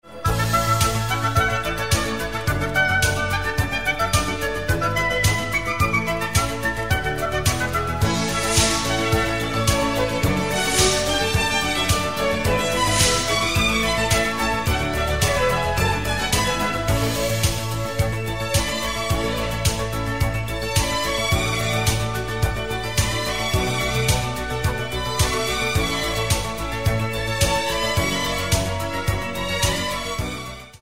in A maj